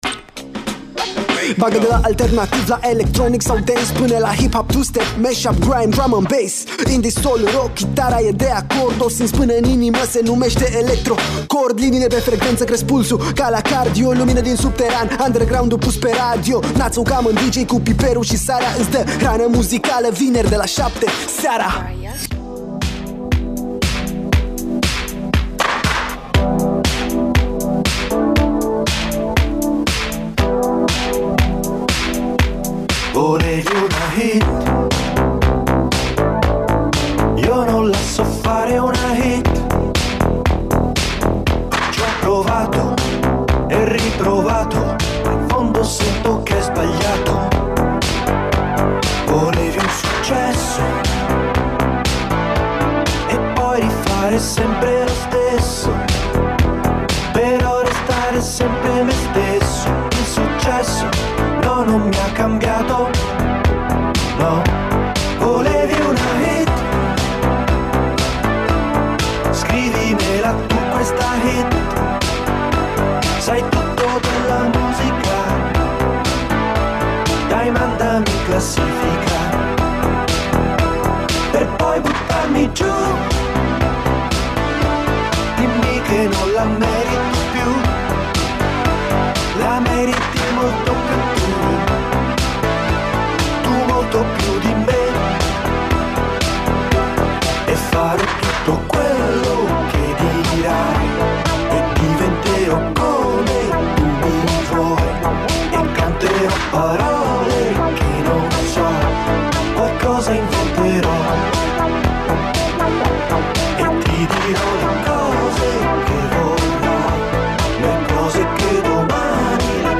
muzicii electronice de dans (EDM)
un mix de piese numai potrivite pentru începutul de weekend